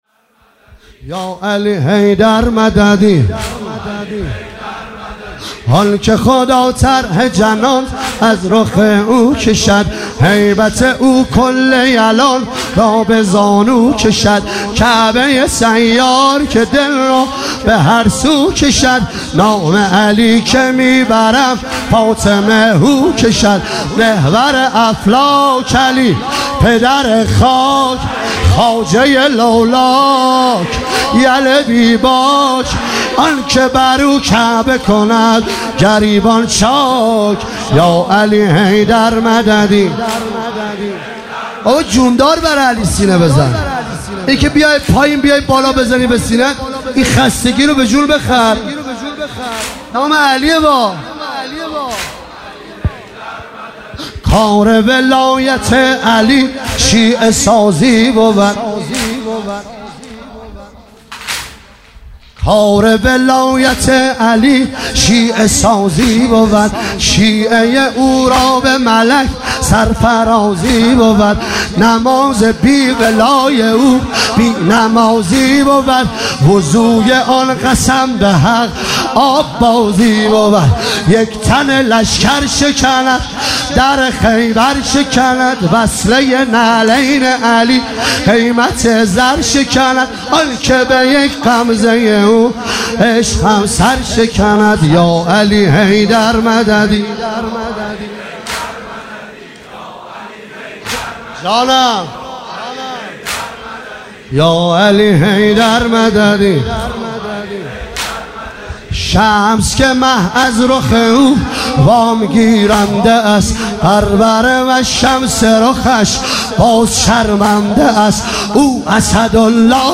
شب هشتم محرم ۱۳۹۹